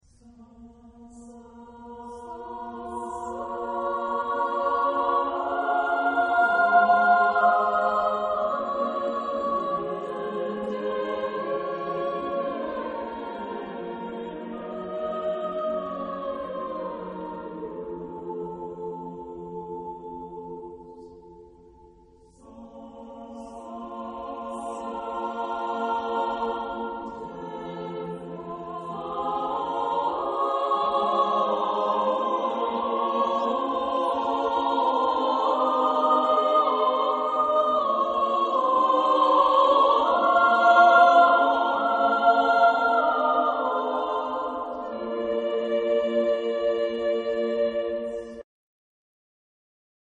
Genre-Stil-Form: geistlich ; Renaissance ; Motette
Chorgattung: SATB  (4 gemischter Chor Stimmen )
Tonart(en): g-moll